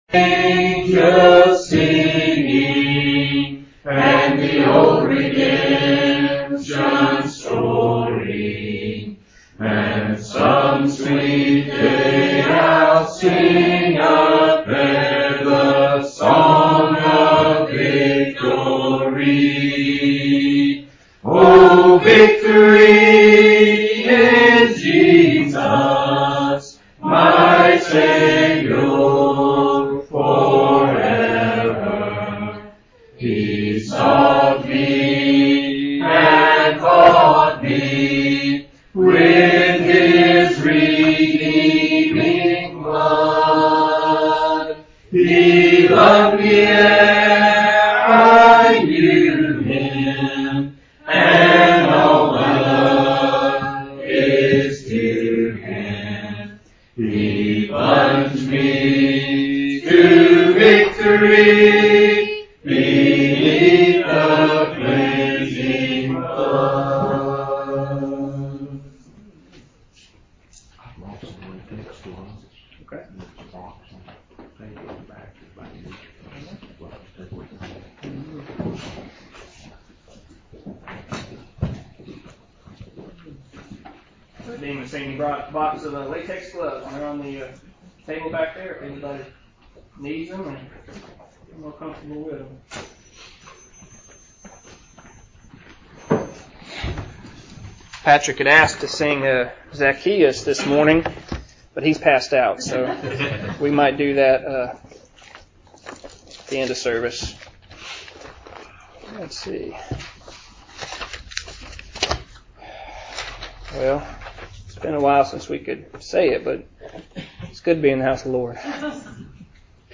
Appears to be an entire worship service but could be a sermon. Note: this one took place in person.